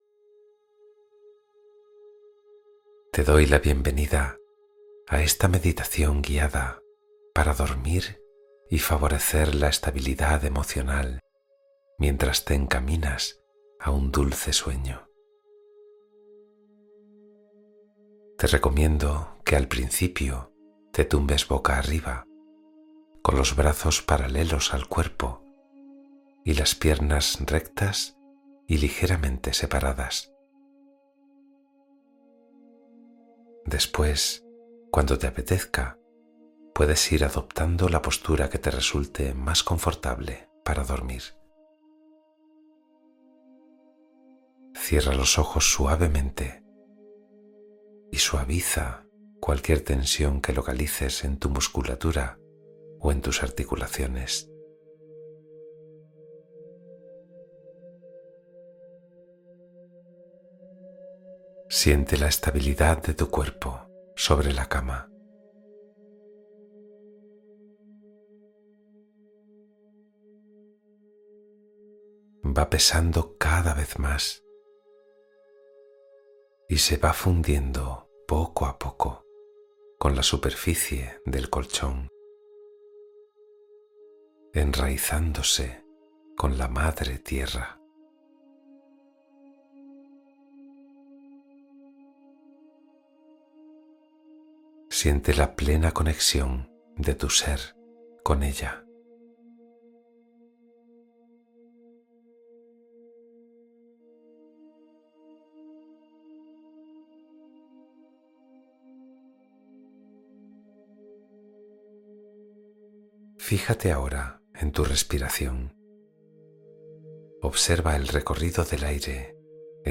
Meditación de la Montaña: Estabilidad Interior para Dormir en Paz